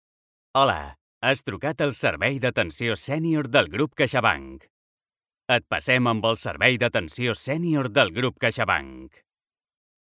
Jeune, Naturelle, Distinctive, Urbaine, Cool
Téléphonie